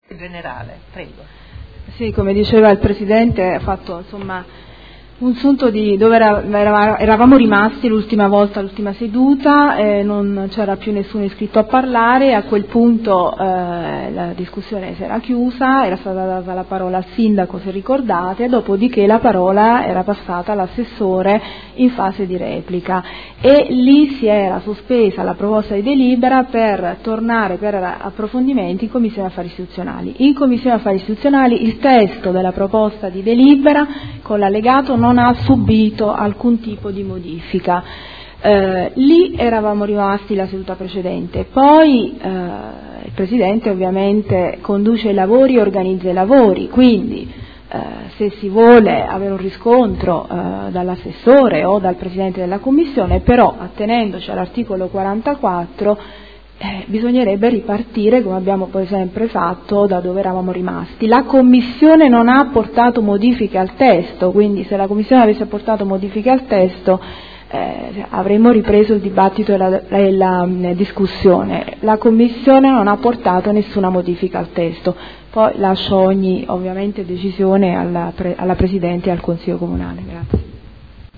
Segretario Generale